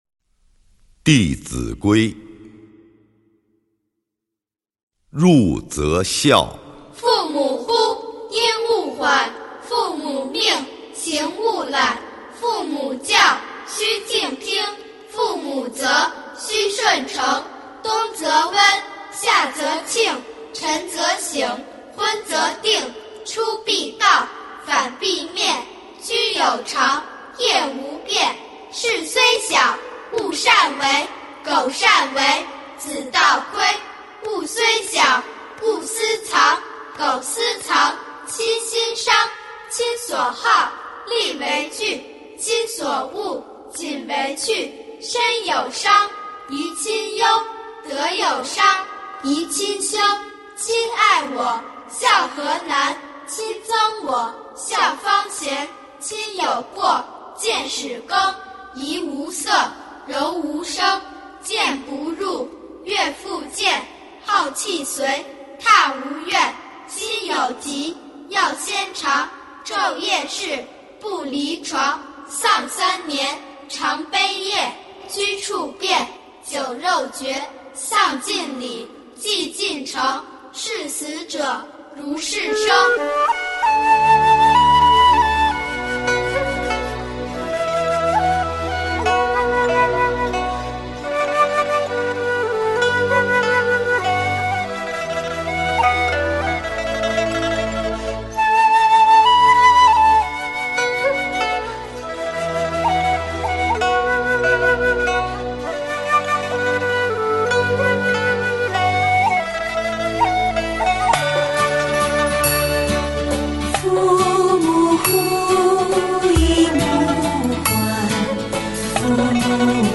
弟子规唱诵版 诵经 弟子规唱诵版--未知 点我： 标签: 佛音 诵经 佛教音乐 返回列表 上一篇： 佛说父母重难报经 下一篇： 娑婆界 相关文章 张开双臂（英文）--The Buddhist Monks 张开双臂（英文）--The Buddhist Monks...